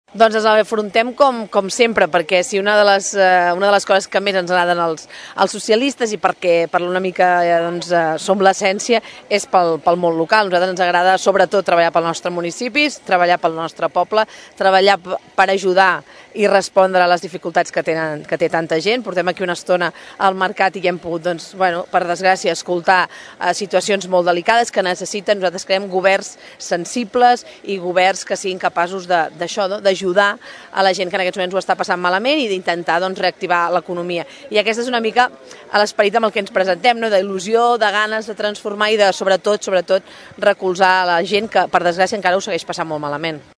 Als micròfons de Ràdio Tordera, Romero va explicar que afronten les eleccions municipals amb la voluntat d’ajudar a tota la gent que ho necessita i reactivar l’economia.